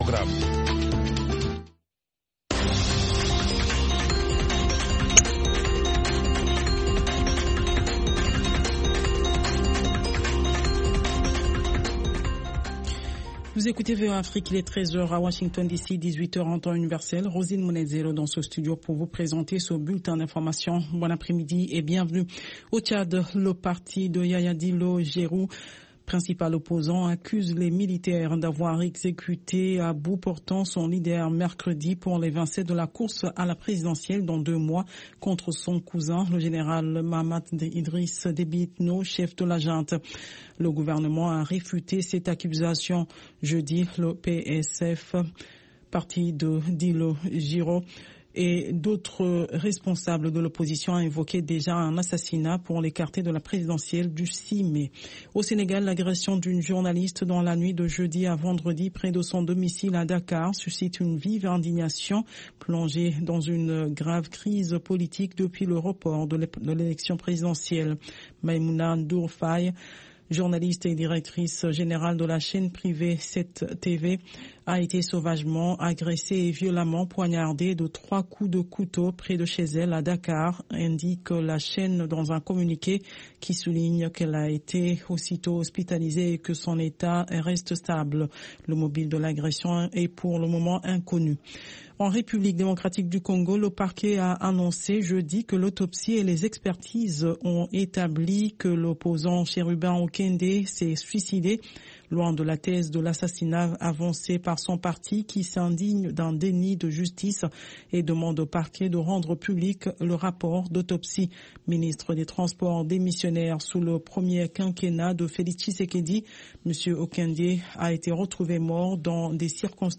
5 Minute Newscast